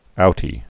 (outē)